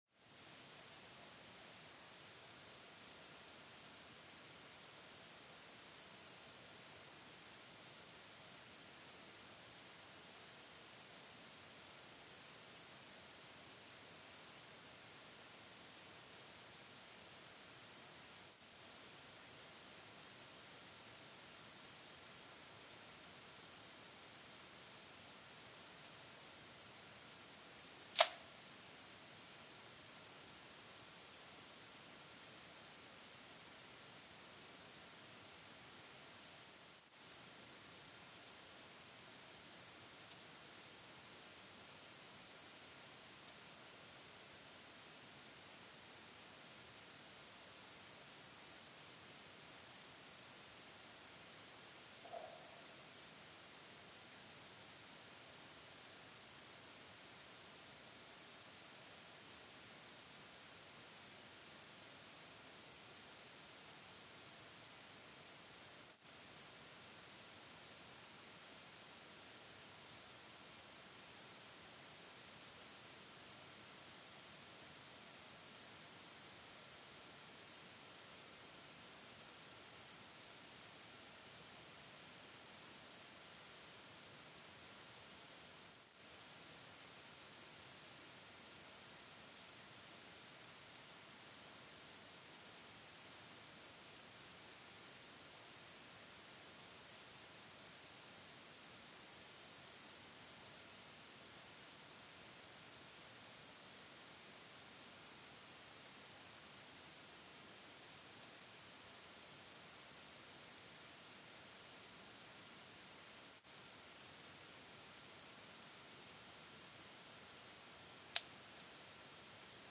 Mbarara, Uganda (seismic) archived on December 22, 2024
Sensor : Geotech KS54000 triaxial broadband borehole seismometer
Speedup : ×1,800 (transposed up about 11 octaves)
Loop duration (audio) : 05:36 (stereo)